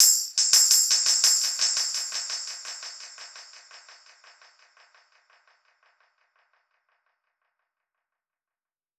Index of /musicradar/dub-percussion-samples/85bpm
DPFX_PercHit_B_85-10.wav